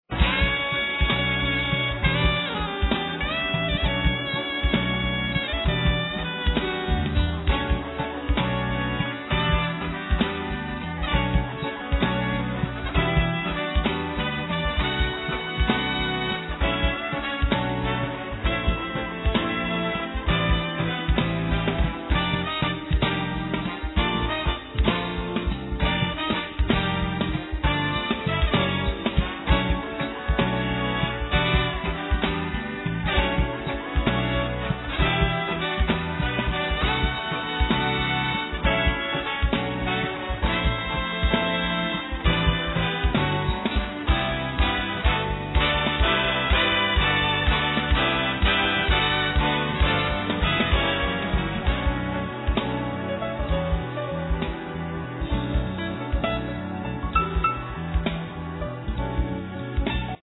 ８０年代フュージョンサウンドがお好きな方にオススメです。